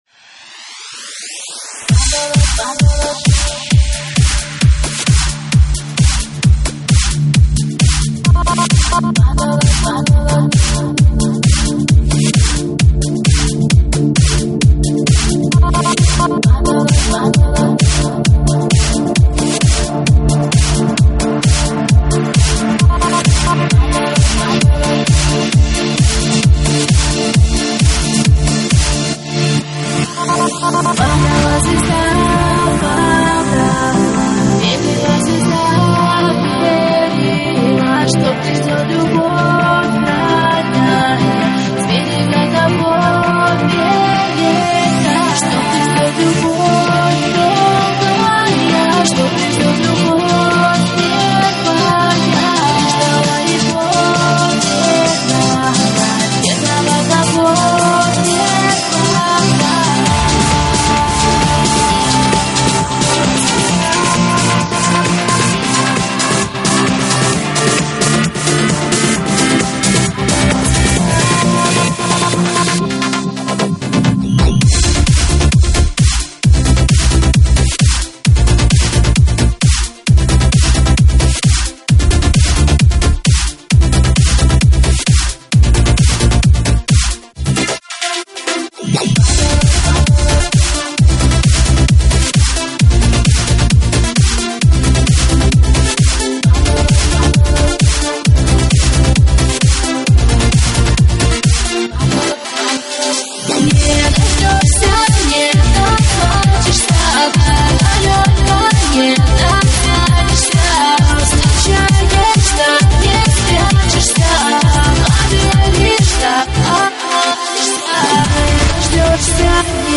Категория: Клубняк | Добавил: Admin